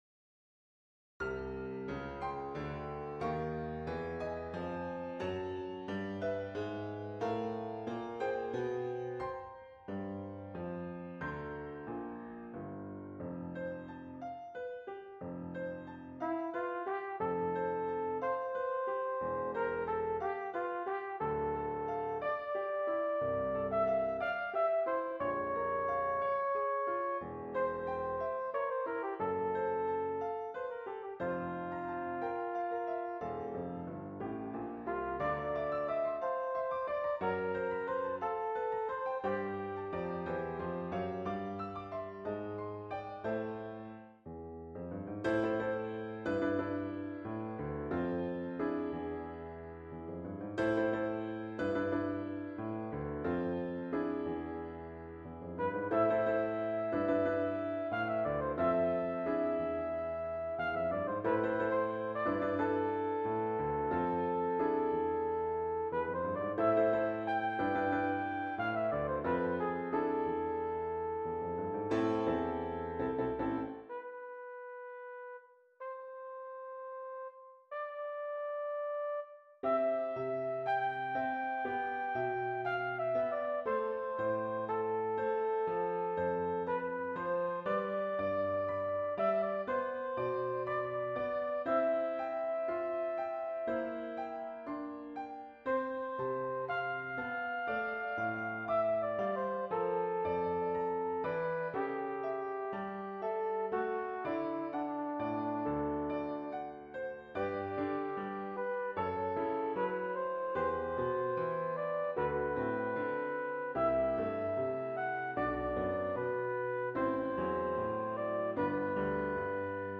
136c - Nocturne pour bugle et piano
136c-Nocturne-for-flugelhorn-and-piano.mp3